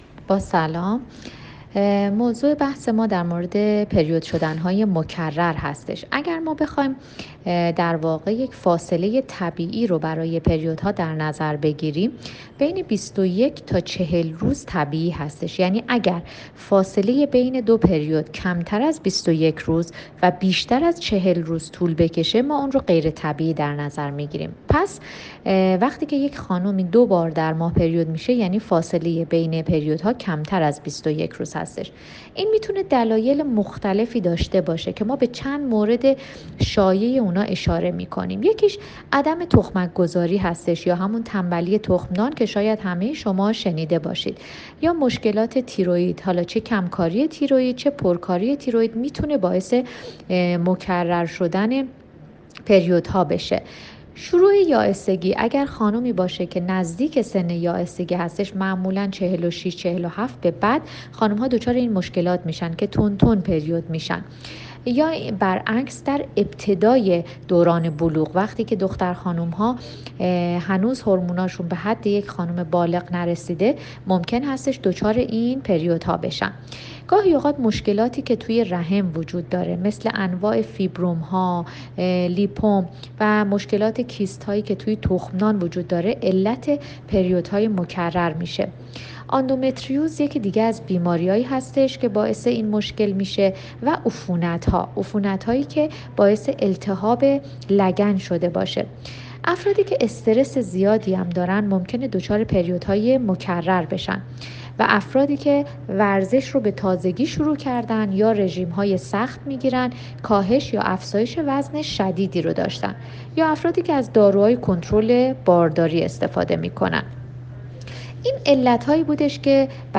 نظر پزشک متخصص درباره پریود های مکرر